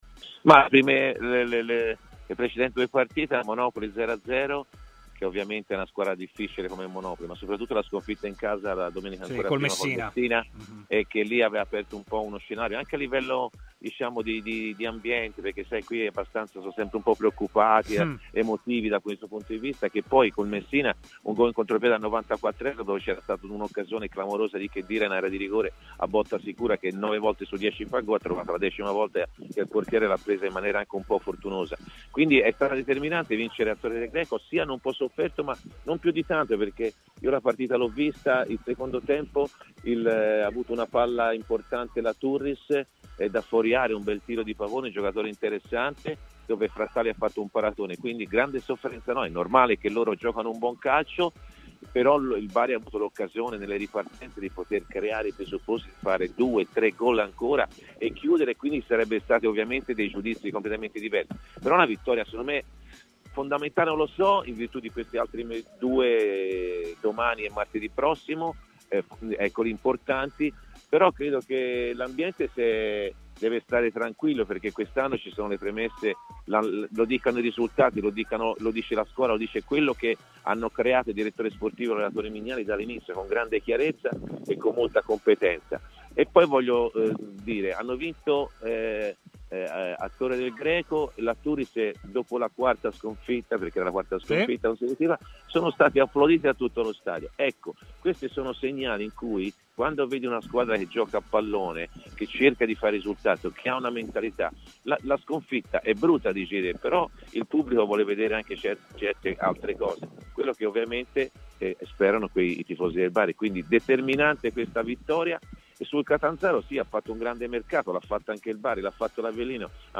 L'ex calciatore del Bari, oggi opinionista, Antonio Di Gennaro, ha commentato il successo dei biancorossi contro la Turris ai microfoni di Stadio Aperto: "Le precedenti due partite, col Monopoli e con il Messina, avevano aperto uno scenario di preoccupazione a livello di ambiente.